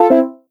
drone.wav